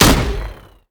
AutoGun_1p_02.wav